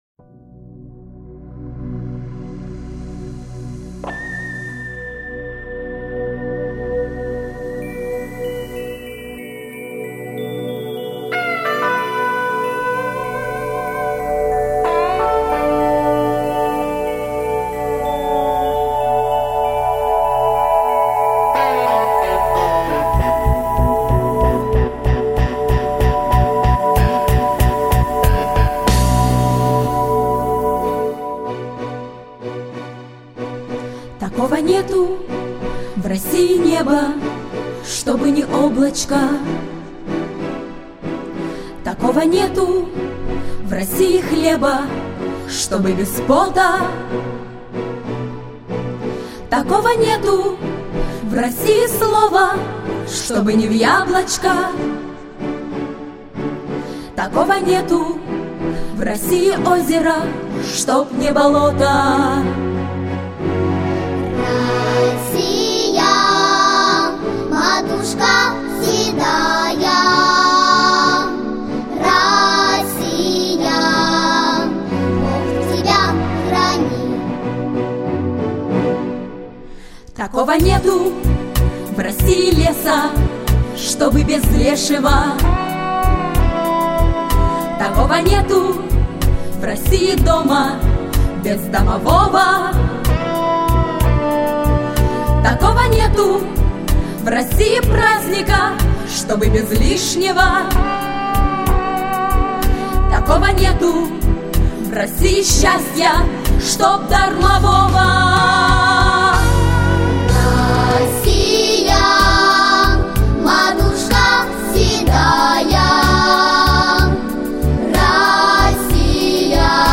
Славянская музыка